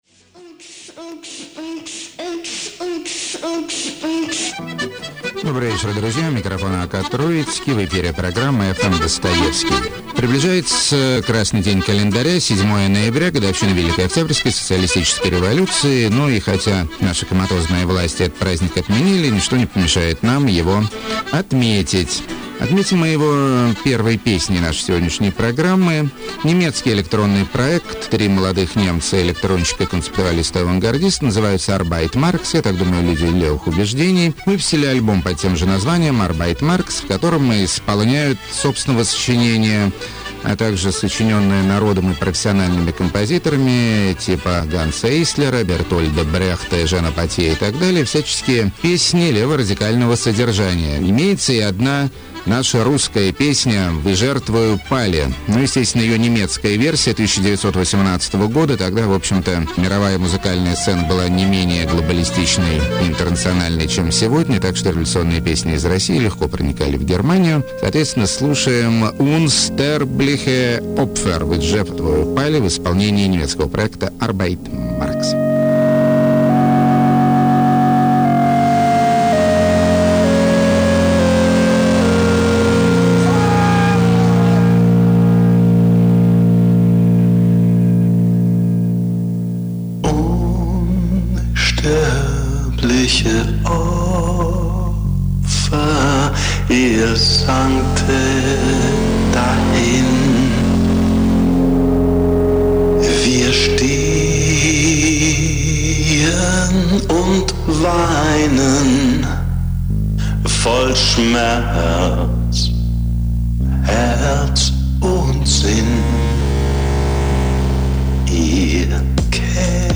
Записано с большими помехами.